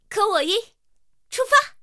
KV-1编入语音.OGG